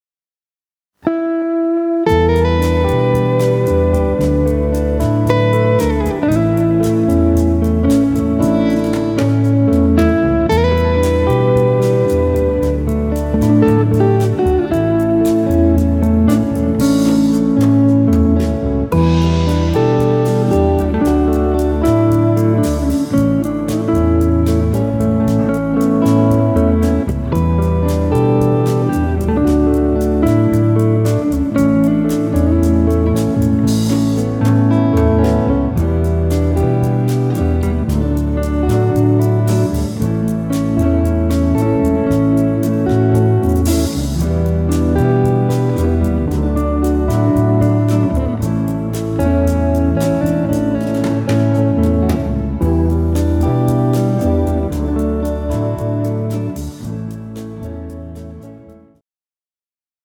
Rock bossa style
tempo 114 bpm
female backing track
This backing track is in soft rock bossanova style.
Female singer version: